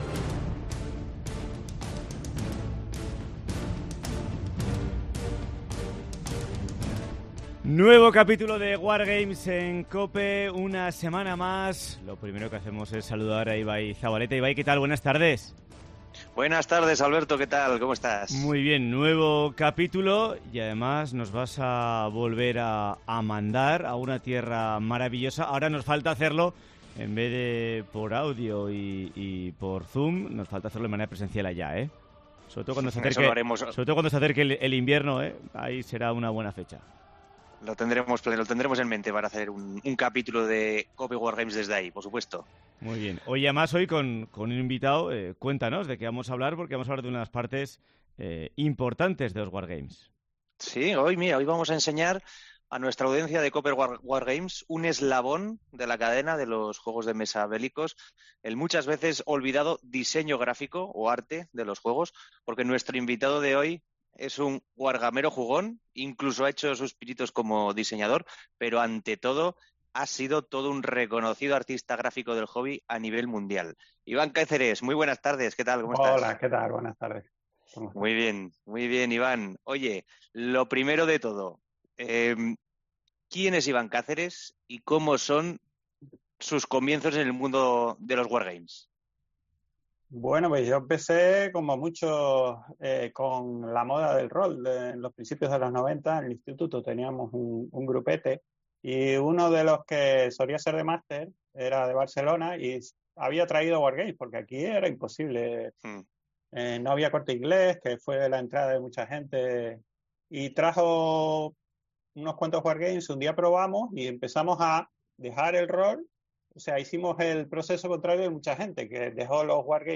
Wargames en COPE: entrevista